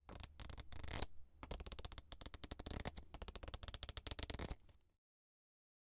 正在洗牌的扑克牌
描述：在这个片段中，一堆卡片正在洗牌。您将听到卡片如何快速翻转和随机播放。这些牌被洗牌3次，每次洗牌顺利过渡到下一张牌。每次随机播放最多使用20张卡片，以获得清晰的录音和清晰的声音。